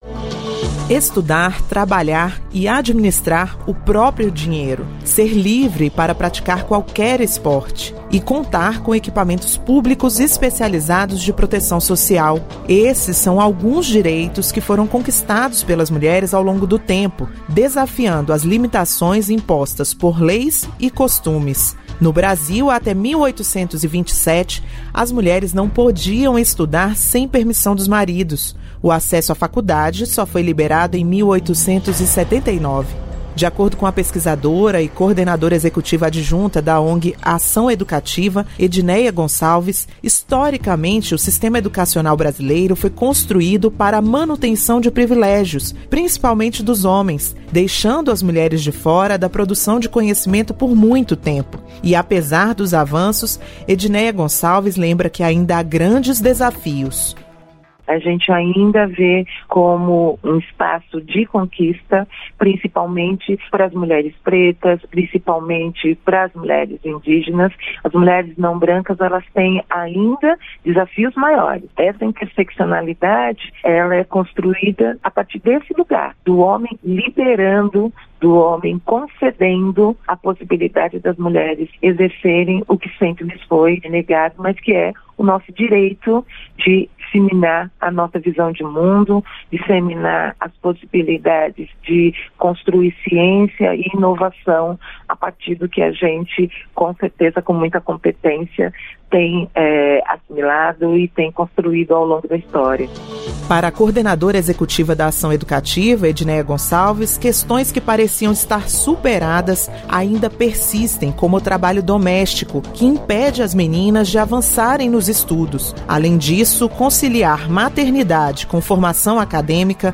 Esta é a primeira reportagem da série especial da Radioagência Nacional que conta as conquistas femininas por direitos no Brasil em diferentes áreas da vida, como trabalho, educação e proteção social.